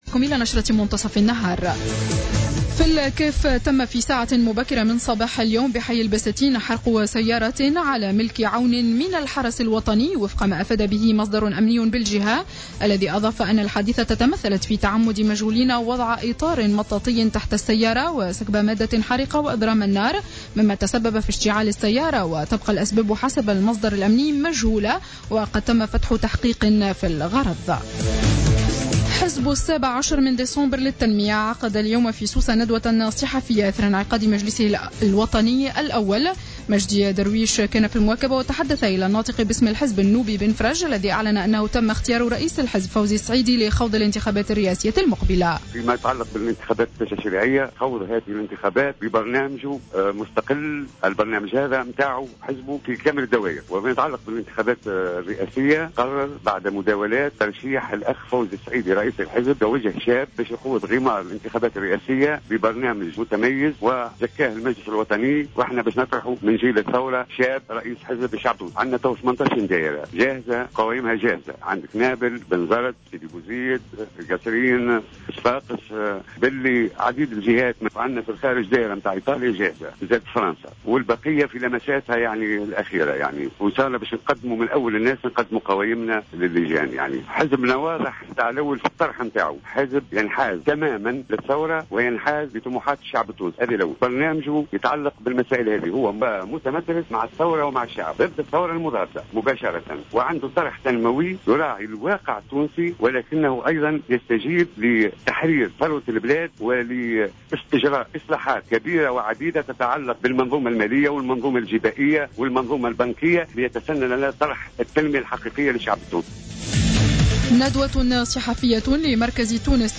نشرة أخبار منتصف النهار ليوم الأحد 10-08-14